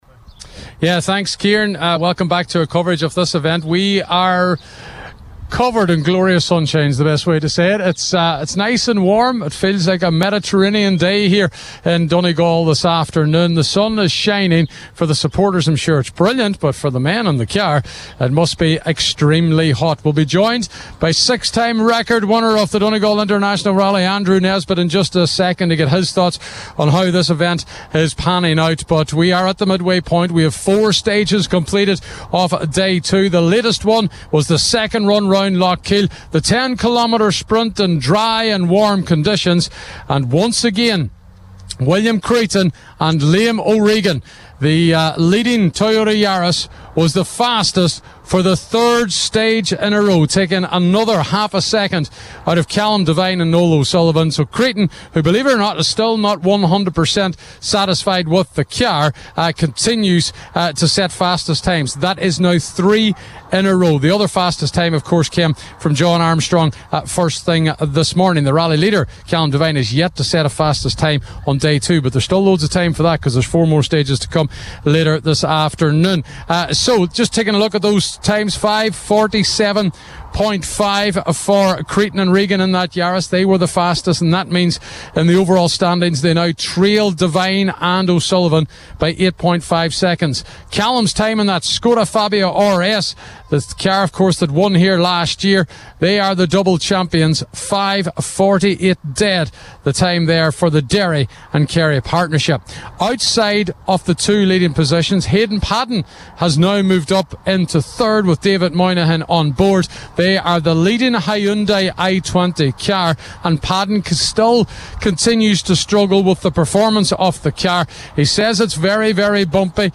reported live from the heart of the action for Highland Radio Sport…